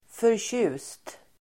Uttal: [förtj'u:st]